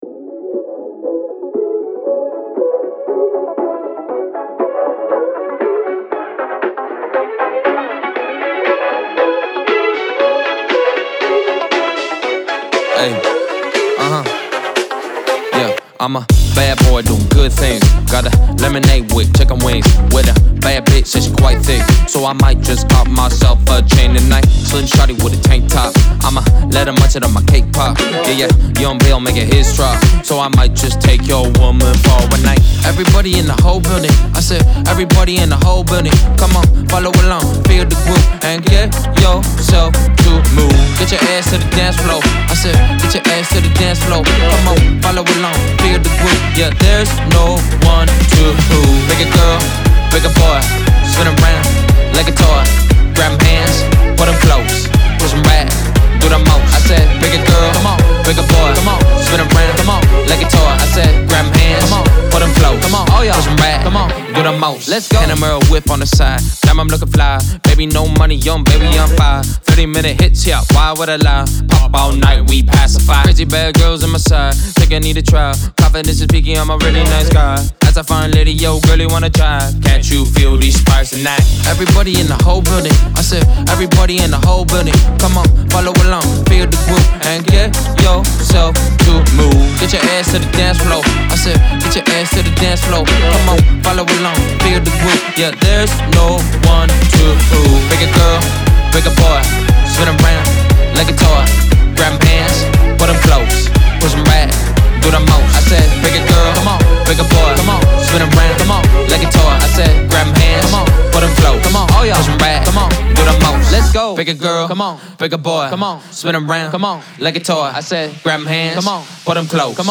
Future Funk